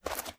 STEPS Dirt, Walk 25.wav